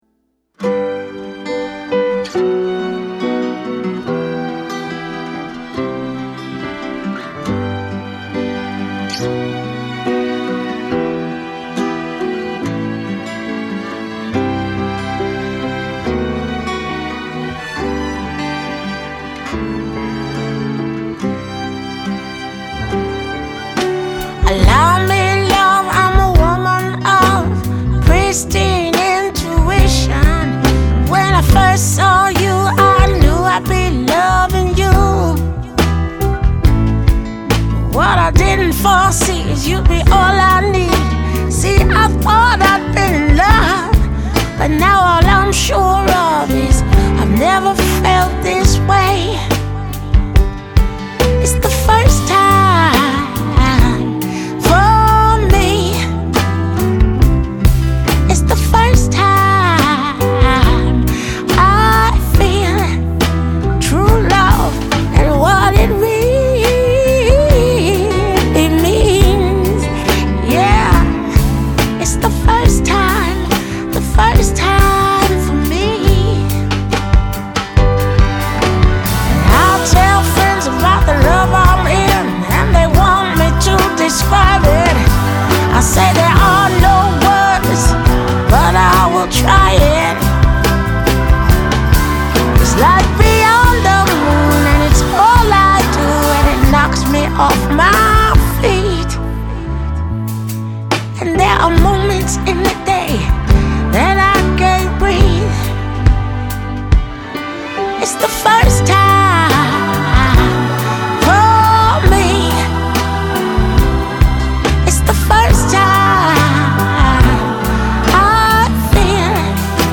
pop and R&B